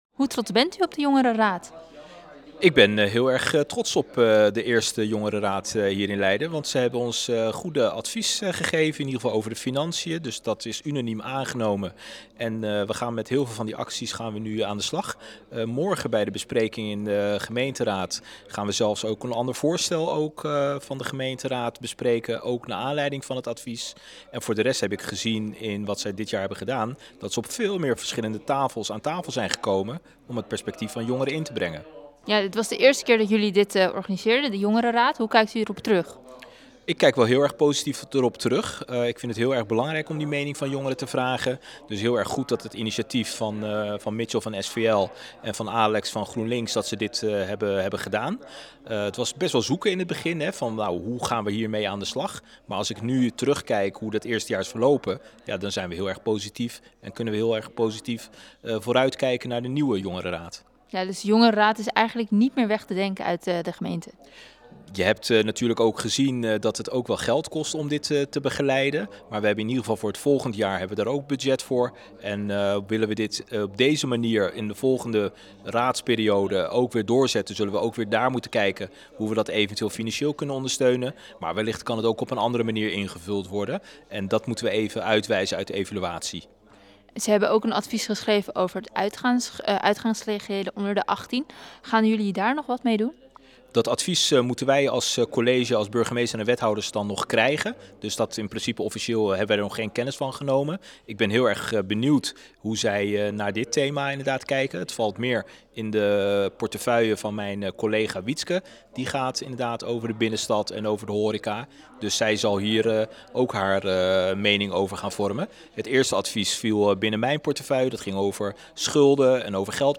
in gesprek met wethouder Abdelhaq Jermoumi over de jongerenraad.